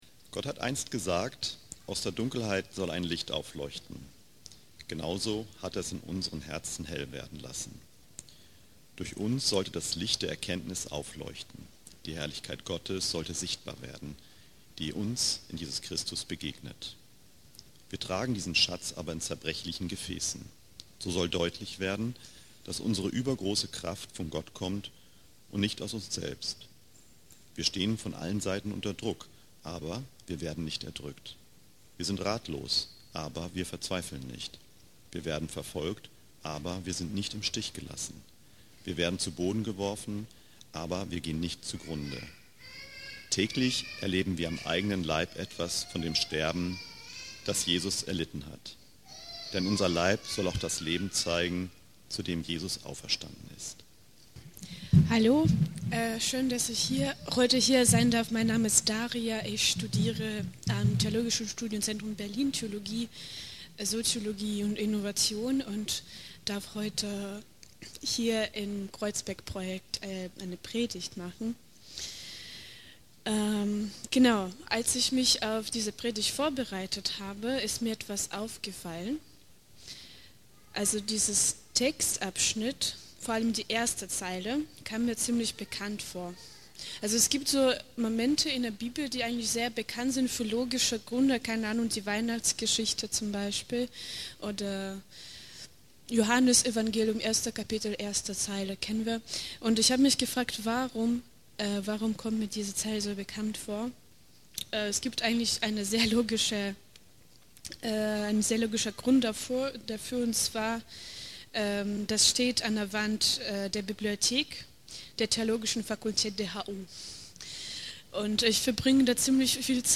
Gastpredigt